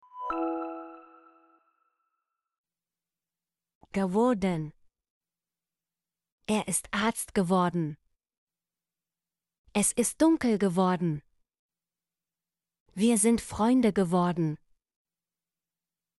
geworden - Example Sentences & Pronunciation, German Frequency List